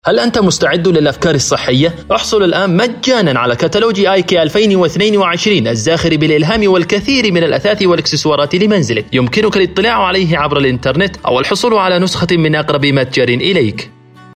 تعليق صوتي - إعلان
نسخة من تعليق صوتي لإعلان تجريبي لصالح شركة آيكيا
معلق صوتي